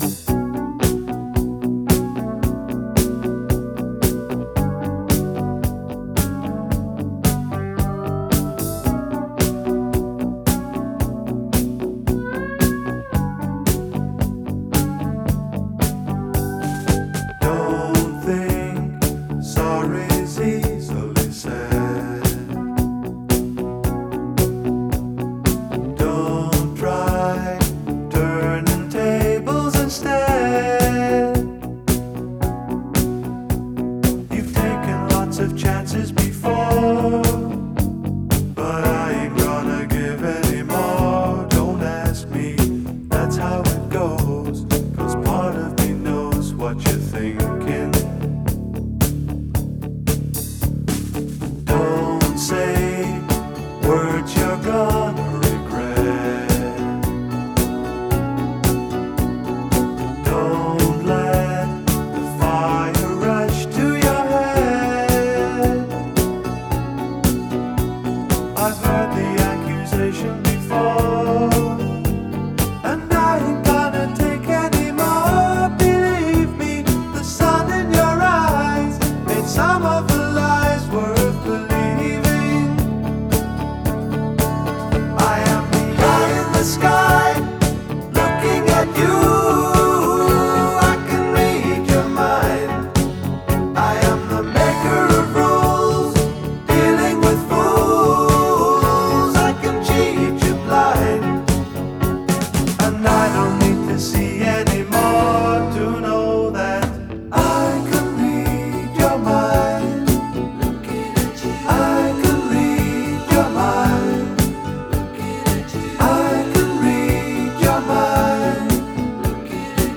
Genre : Rock.